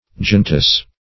Search Result for " giantess" : Wordnet 3.0 NOUN (1) 1. a female giant ; The Collaborative International Dictionary of English v.0.48: Giantess \Gi"ant*ess\, n. A woman of extraordinary size.